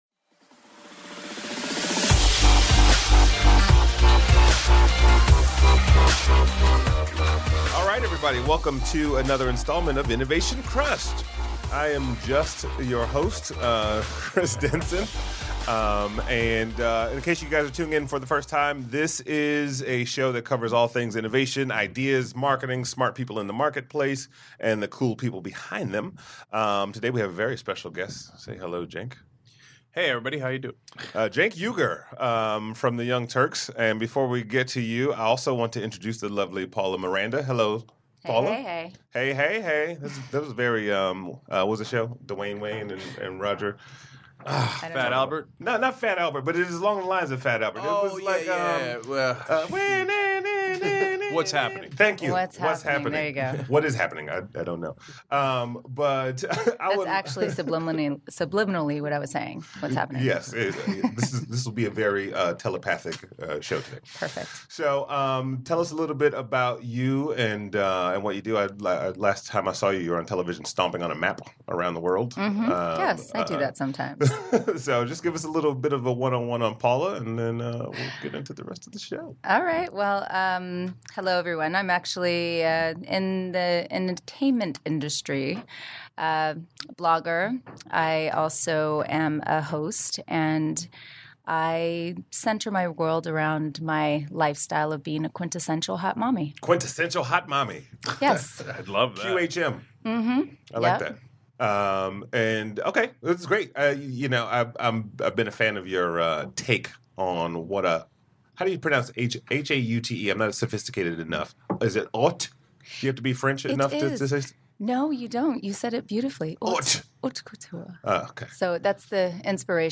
feat special guest co-host